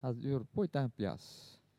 Localisation Notre-Dame-de-Monts
Catégorie Locution